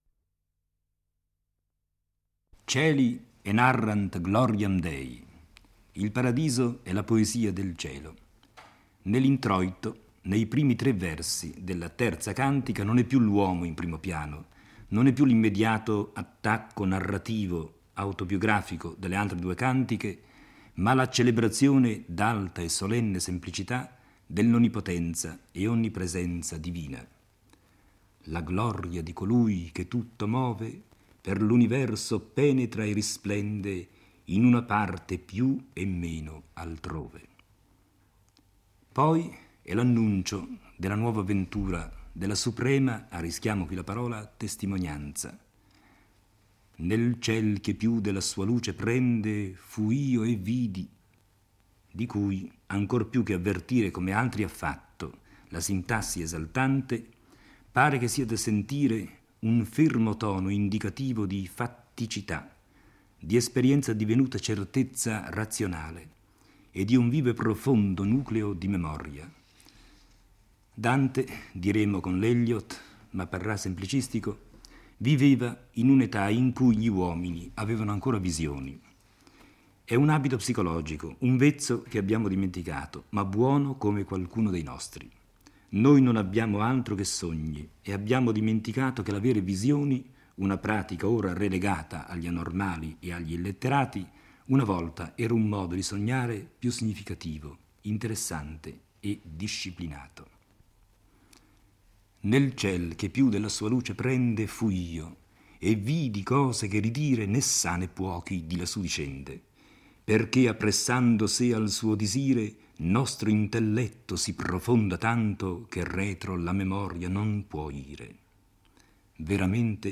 legge e commenta il I canto del Paradiso.